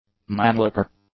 mannlicher_english.mp3